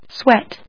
/swét(米国英語), swet(英国英語)/